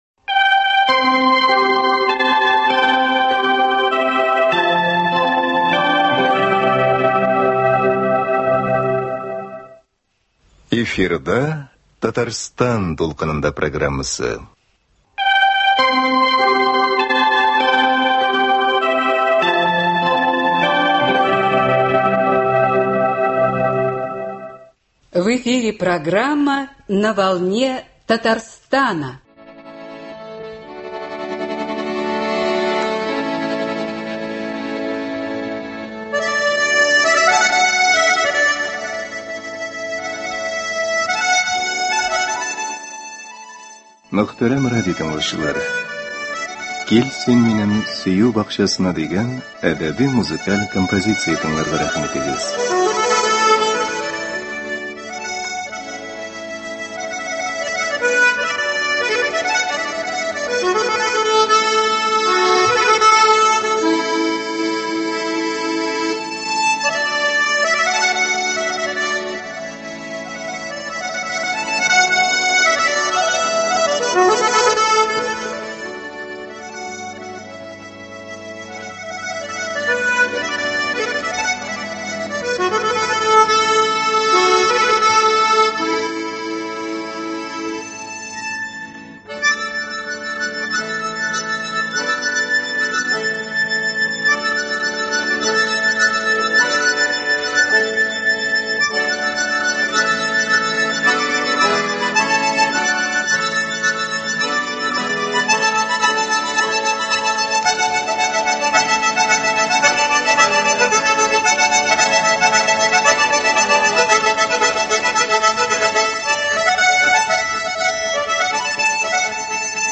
Әдәби-музыкаль композиция.